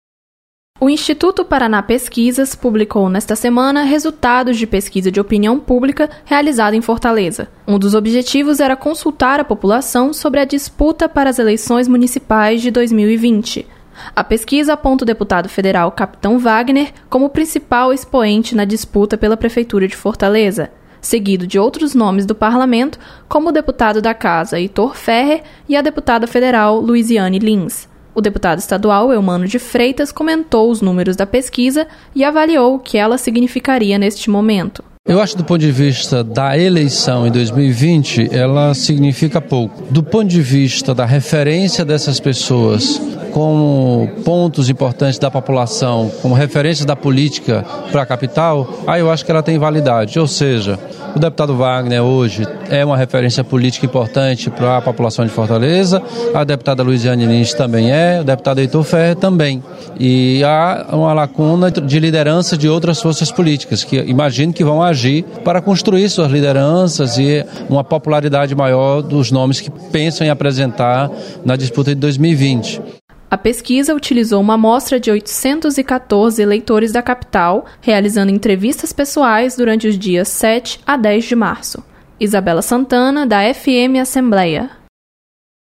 Deputado comenta pesquisa de opinião sobre eleições municipais.